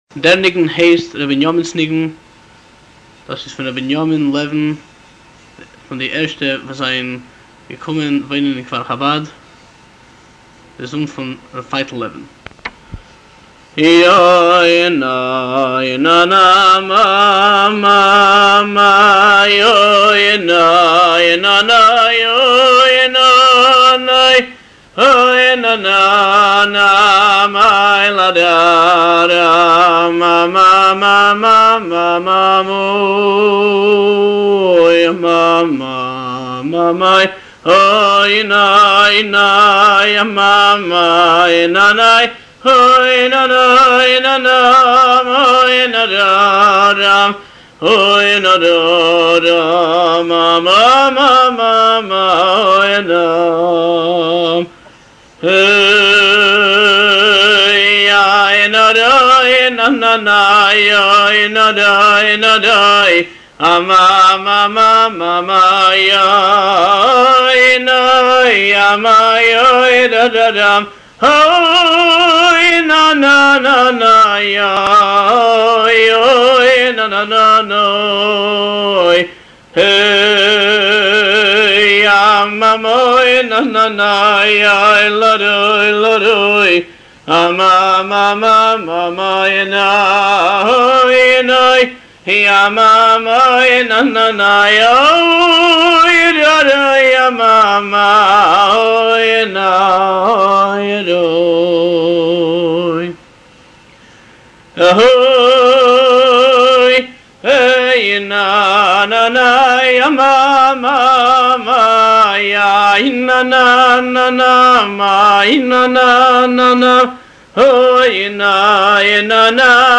ניגון געגועים איטי